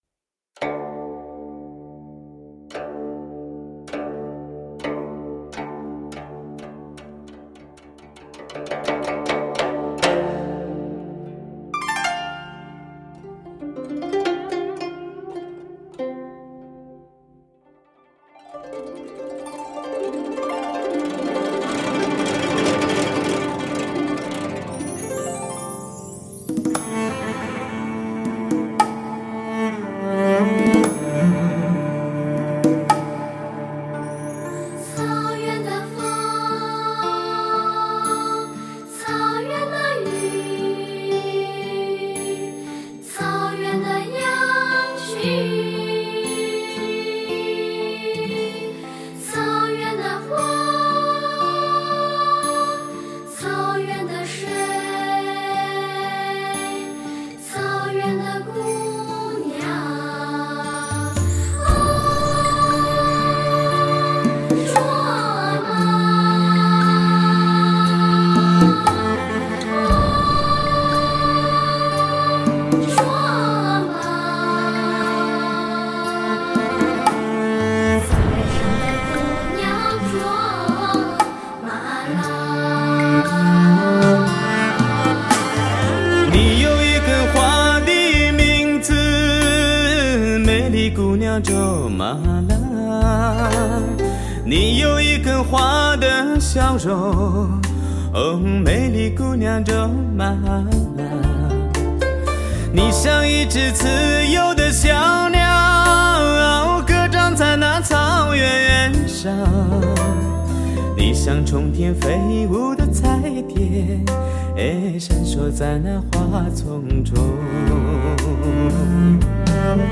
各大音乐网站点击率最高的热门草原歌曲，最天然最优美的声音，干净淳厚，
犹如草原上清冽的风！
纯粹的草原风格，配以时尚流行的音乐元素。